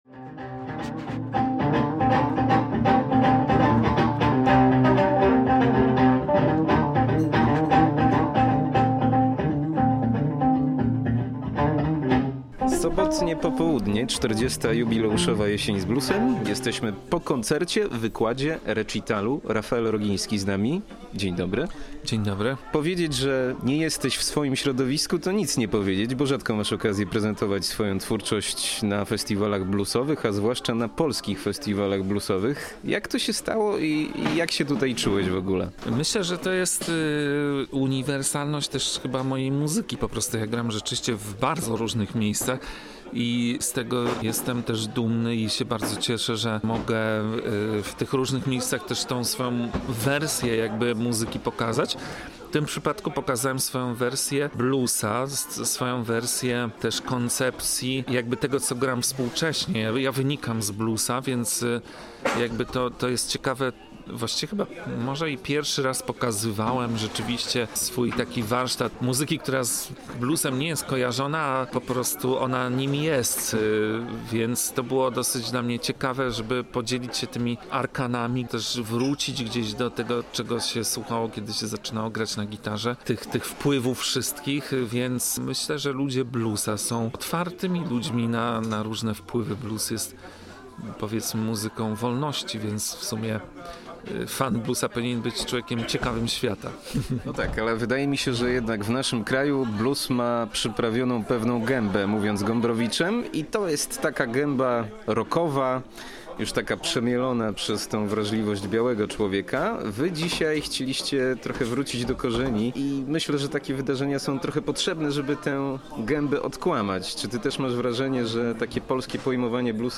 Gdzie szukać dziś muzyki najbliższej afrykańskim korzeniom bluesa? I czy nagra kiedyś płytę stricte bluesową? Posłuchajcie rozmowy z artystą.